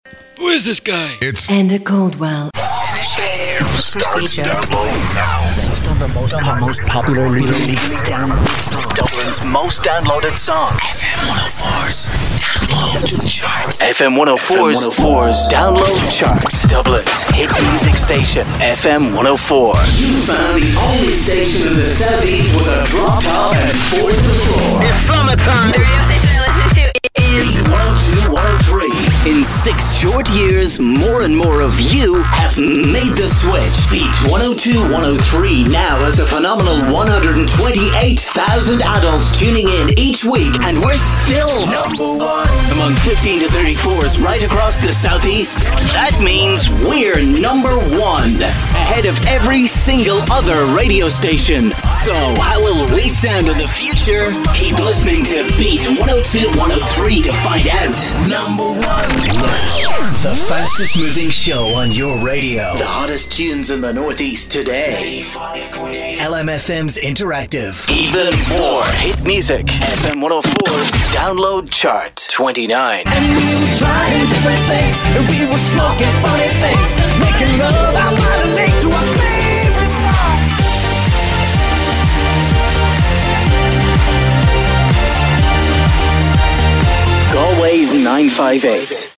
Irish Voice Over, Fresh, Engaging, Clear, A voice that cuts right through, Male, Young, Middle, Irish, Ireland, UK, English, European, Different, Specialist, Radio Imaging, Commercial Reads, Advertising, Natural, Warm, Flow, Popular, Bubbly.
Sprechprobe: Sonstiges (Muttersprache):
Irish worldwide voice artist